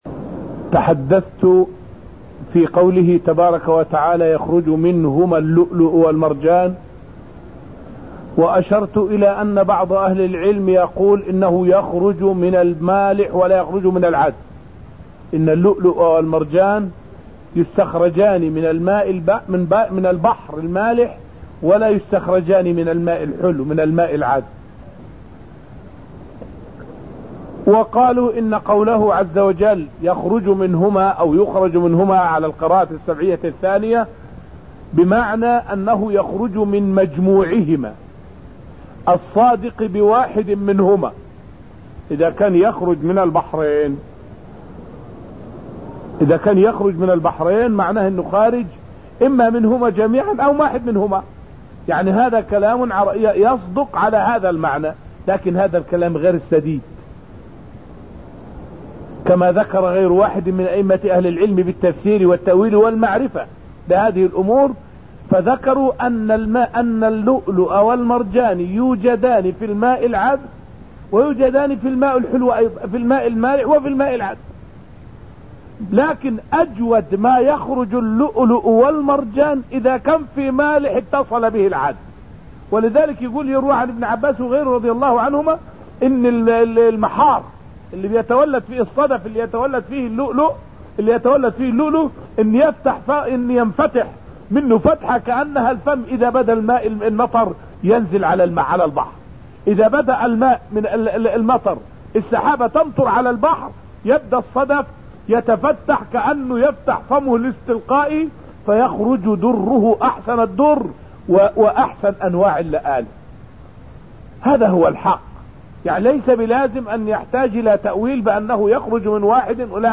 فائدة من الدرس الثامن من دروس تفسير سورة الرحمن والتي ألقيت في المسجد النبوي الشريف حول تفسير "يخرج منهما اللؤلؤ والمرجان".